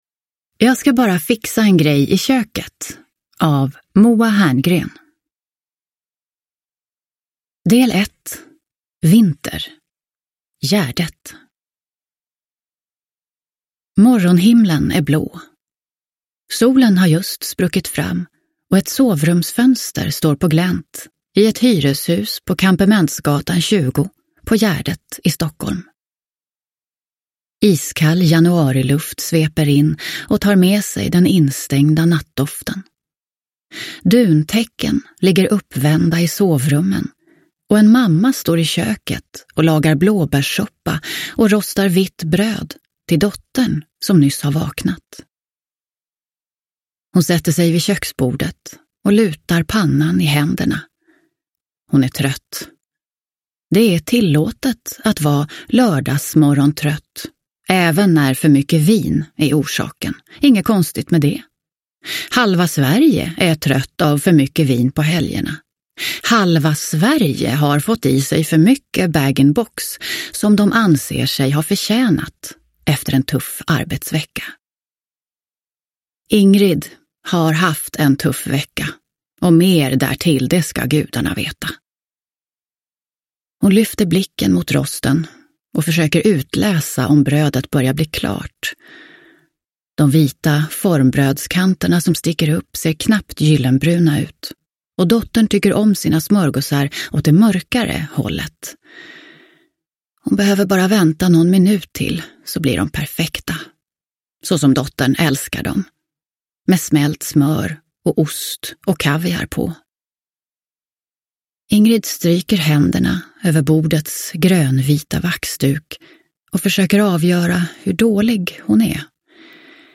Jag ska bara fixa en grej i köket – Ljudbok – Laddas ner